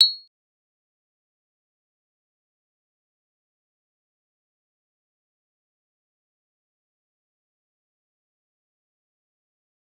G_Kalimba-B8-mf.wav